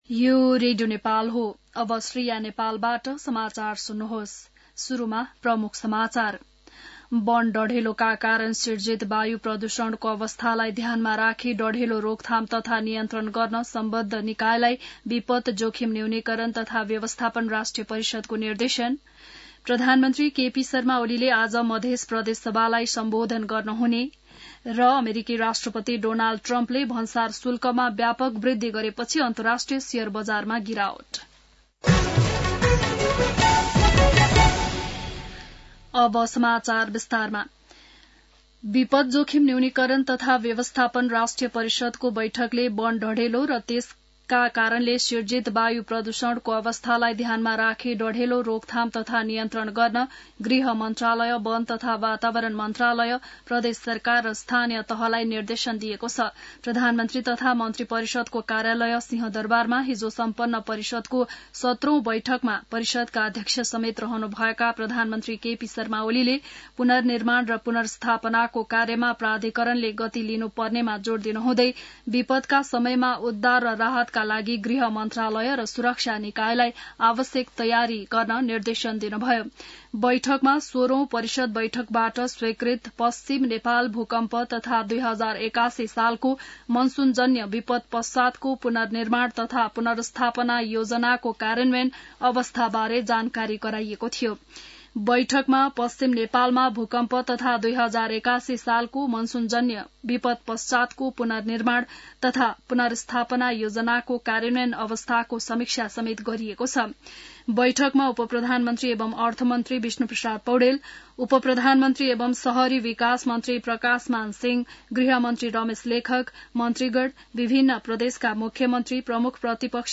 बिहान ९ बजेको नेपाली समाचार : २६ चैत , २०८१